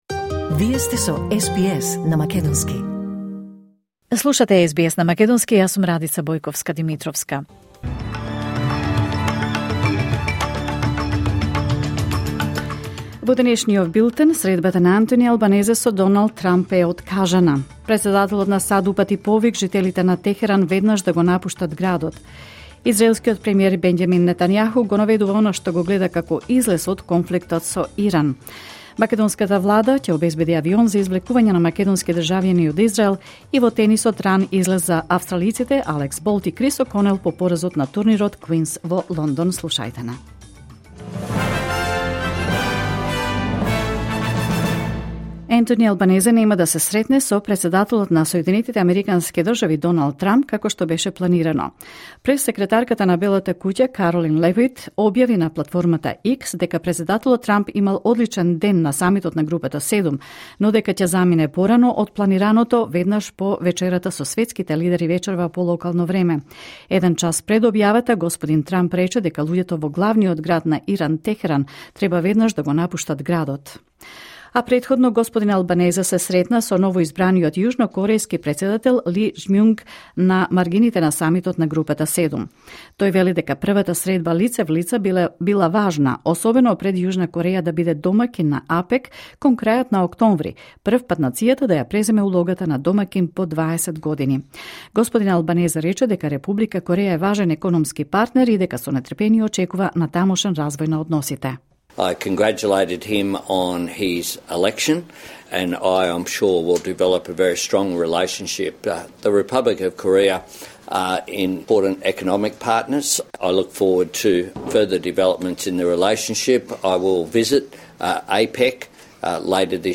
Вести на СБС на македонски 17 јуни 2025